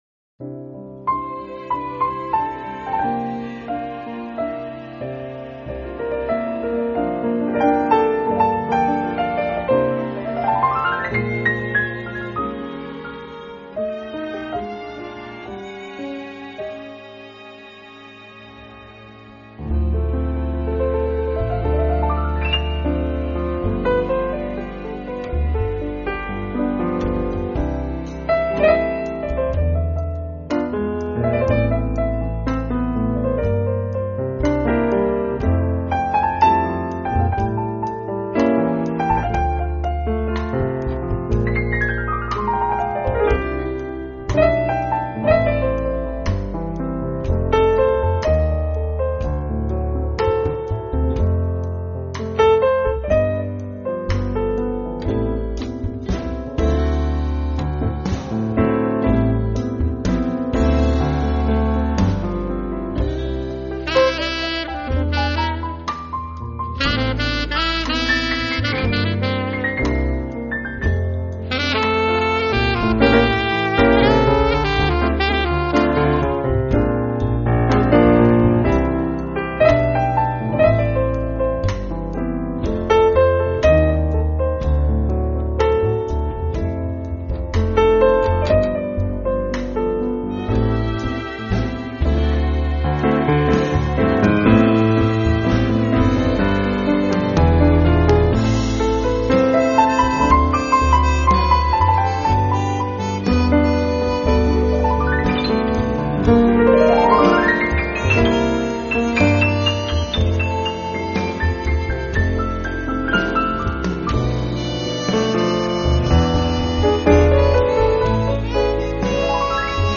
他们把这些原本流行的歌曲改编为带有明显爵士味道的纯音乐，
拥有了爵士音乐特有的浪漫，却没有爵士音乐的吃力和震荡，
这些作品却显得那么的舒缓和柔美，轻盈飘荡间，